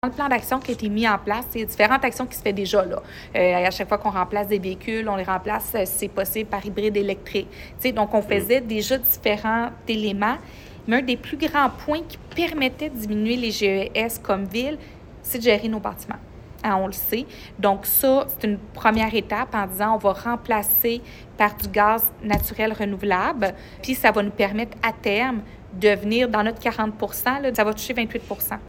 La mairesse de Granby, Julie Bourdon.